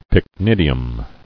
[pyc·nid·i·um]